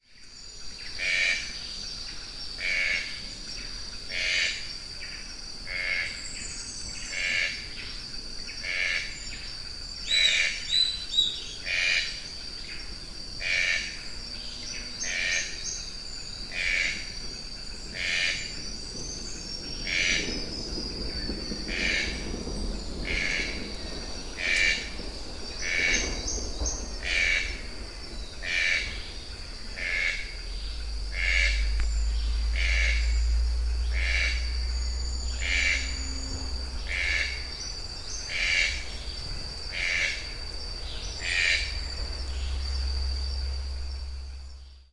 Tucano-de-bico-verde (Ramphastos dicolorus)
Fase da vida: Adulto
Localidade ou área protegida: Parque Nacional Caazapá
Condição: Selvagem
Certeza: Gravado Vocal
Ramphastos-dicolorus.mp3